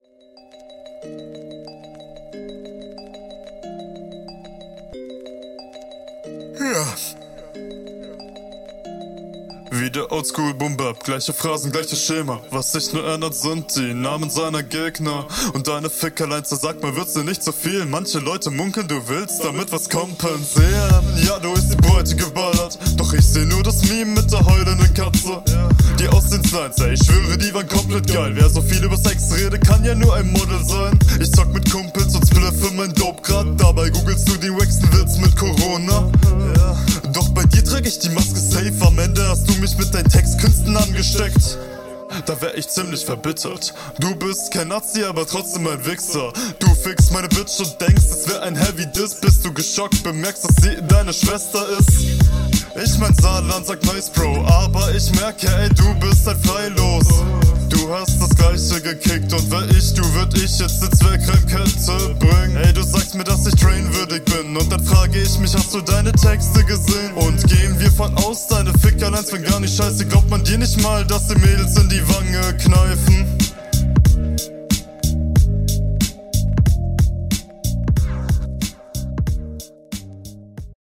Deine Stimme und deine Abmische ist druckvoller, geht einfach durch die Abmische mehr in die …
Als Einstieg kommt dieses Gähnen und das war in Kombination mit dem Beat definitiv witzig.